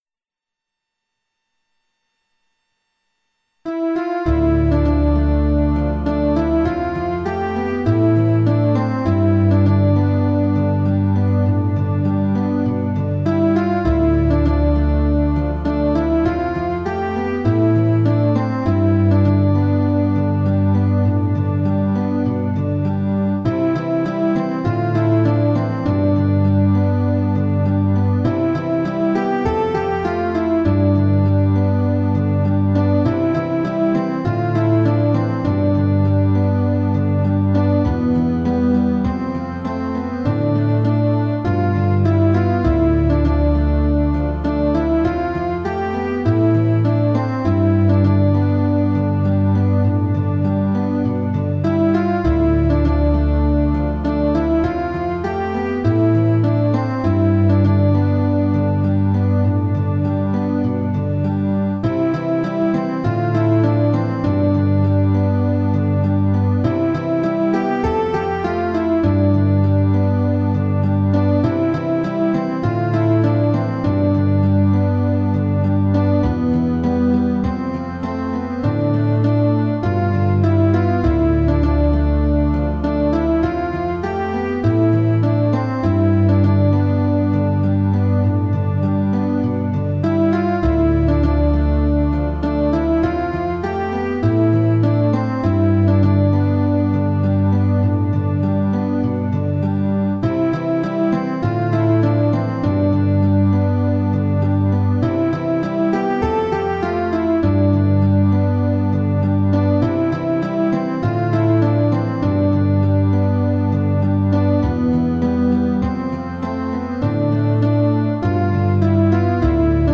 A rousing tribute to God.